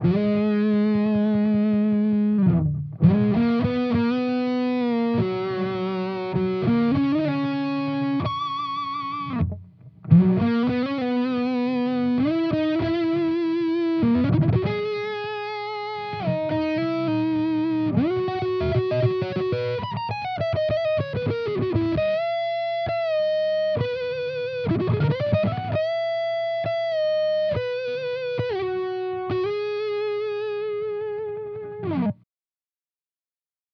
Here are a few quick demos of my custom overdrive/distortion pedals for your listening pleasure.
Purple pedal
I used a Sennheiser MD441-U microphone on-axis, edge of speaker cap, and about an inch away from the grill.
purple-pedal.wav